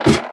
ladder5.ogg